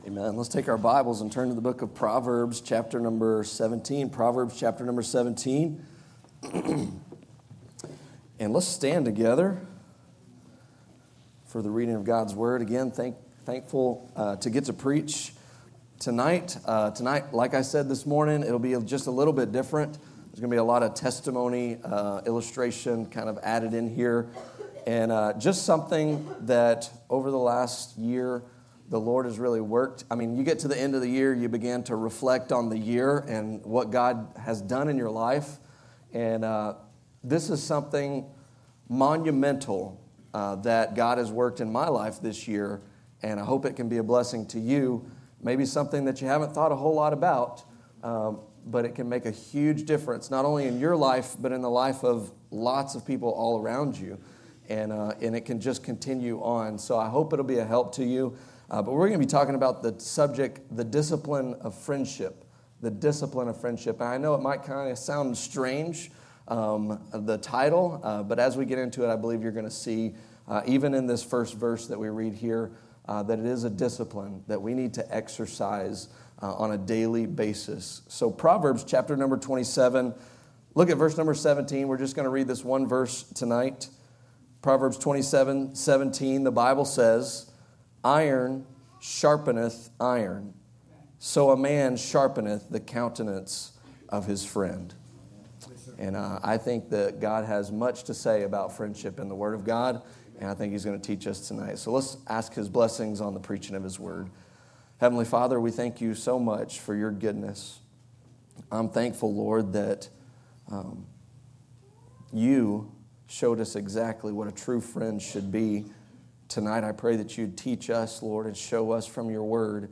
" Guest & Staff Preachers " Guest & Staff Preachers at Bethany Baptist Church Scripture References: Proverbs 27:1-17